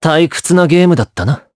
Esker-Vox_Victory_jp.wav